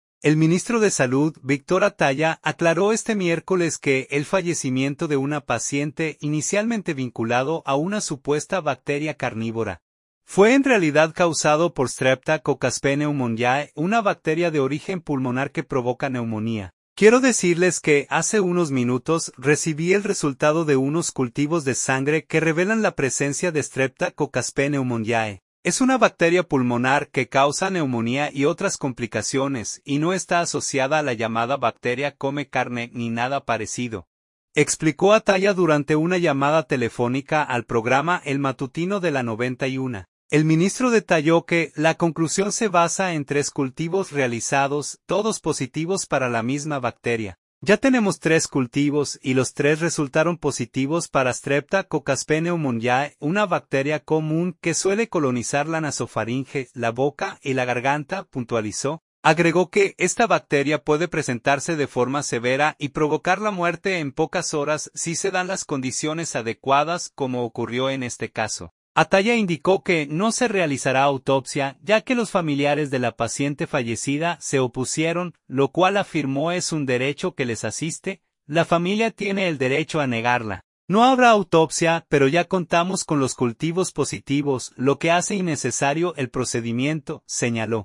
"Quiero decirles que hace unos minutos recibí el resultado de unos cultivos de sangre que revelan la presencia de Streptococcus pneumoniae. Es una bacteria pulmonar que causa neumonía y otras complicaciones, y no está asociada a la llamada ‘bacteria come carne’ ni nada parecido", explicó Atallah durante una llamada telefónica al programa “El Matutino de la 91”.